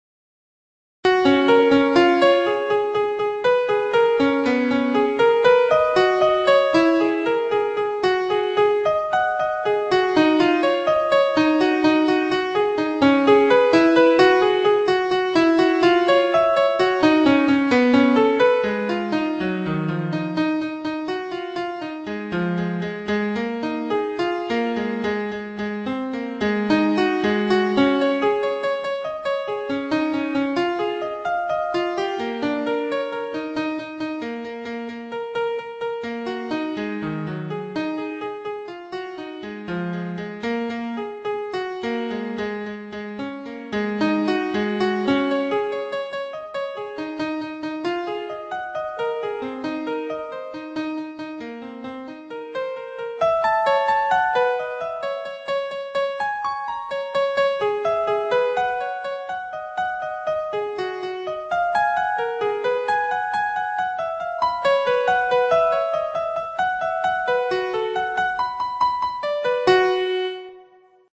(The timing and dynamics are composed by the program and played by a MIDI player.)